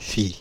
Ääntäminen
Ääntäminen France (Île-de-France): IPA: /fi/ Tuntematon aksentti: IPA: /fit/ Haettu sana löytyi näillä lähdekielillä: ranska Käännöksiä ei löytynyt valitulle kohdekielelle.